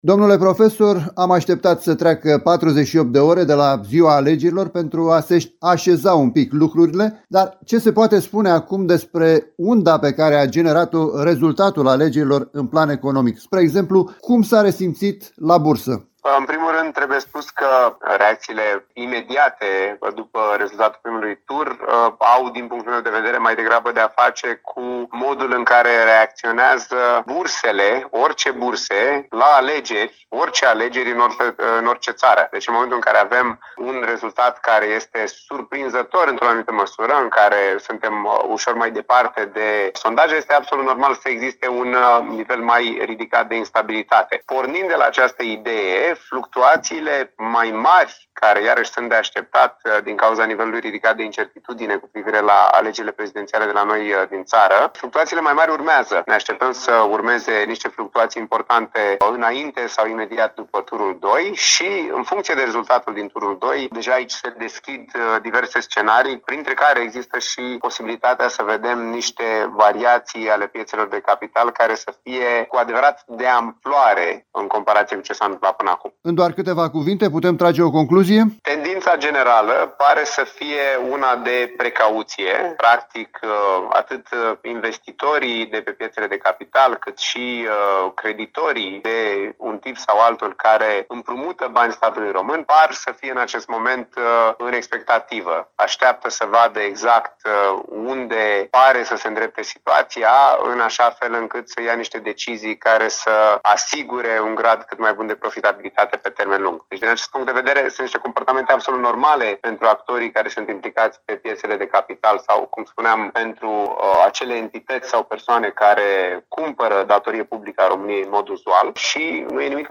O analiză
interviu